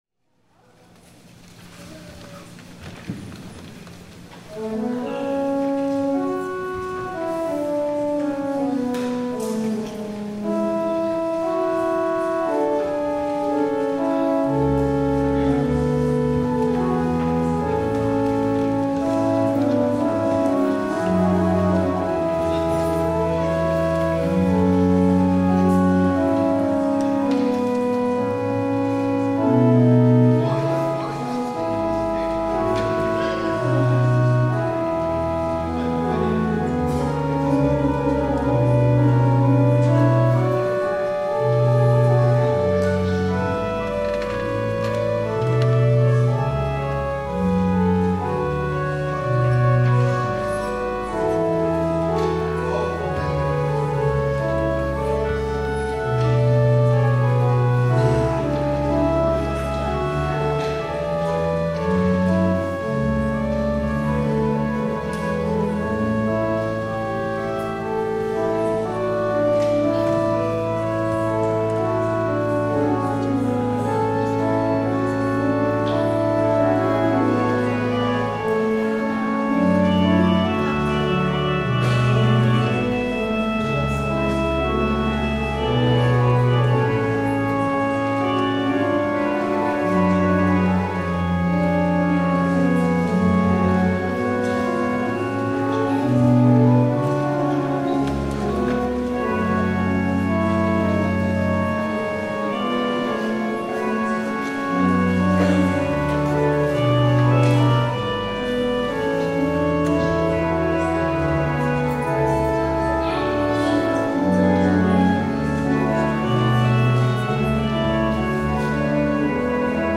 TRINITY SUNDAY
THE PRELUDE